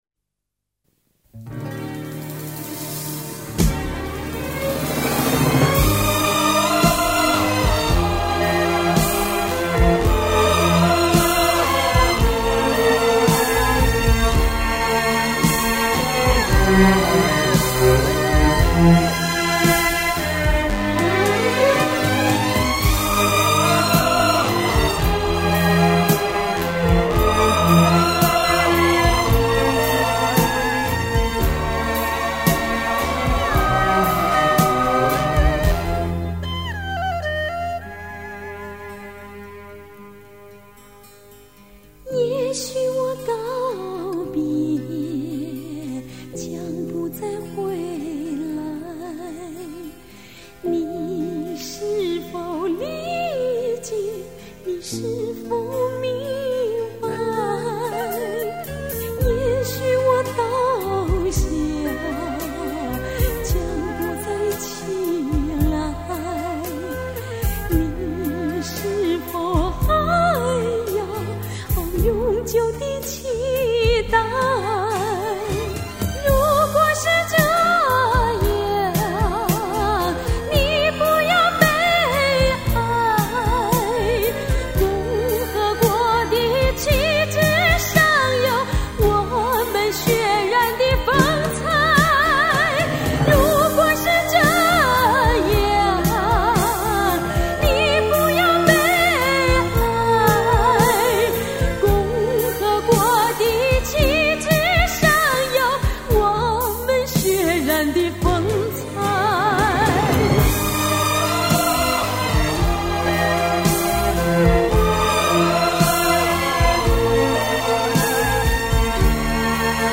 当年“西北风”曲风在中国当道，也是中国流行音乐的原创时代和黄金年代。